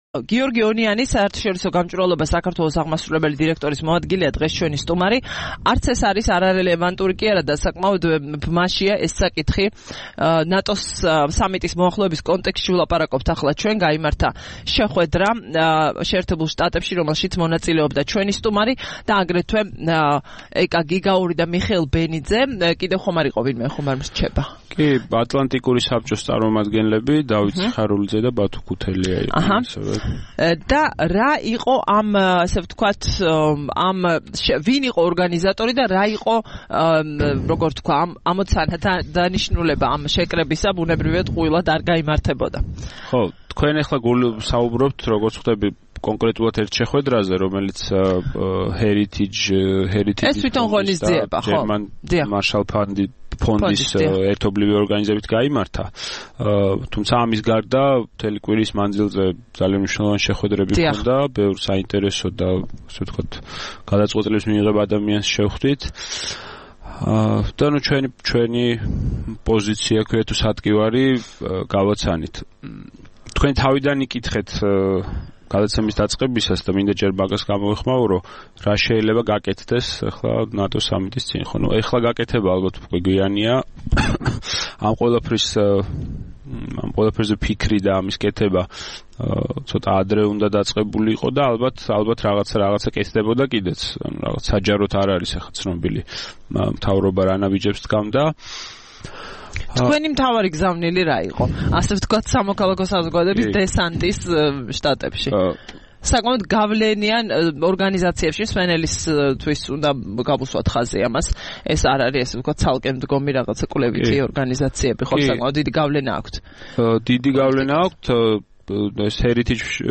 3 ივლისს რადიო თავისუფლების "დილის საუბრების" სტუმარი იყო